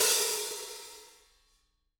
R_B Hi-Hat 08 - Close.wav